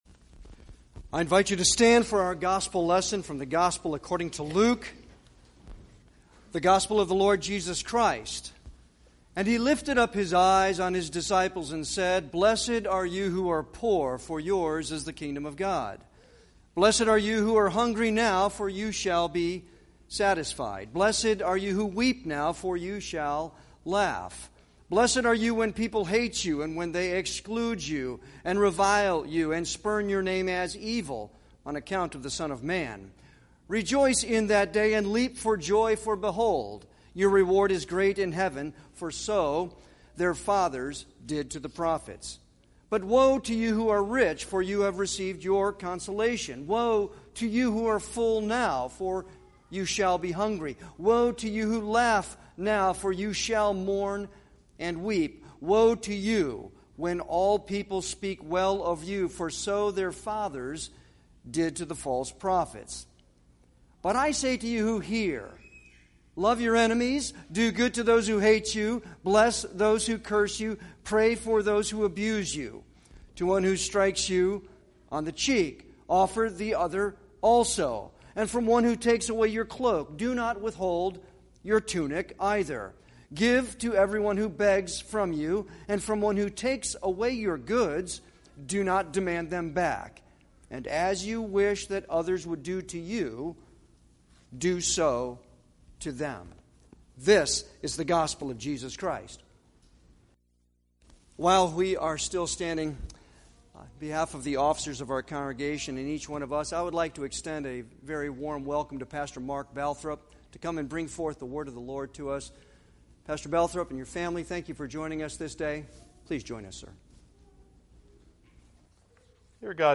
Series: Sermons by visiting preachers Passage: Hebrews 11:39-12:2 Service Type: Sunday worship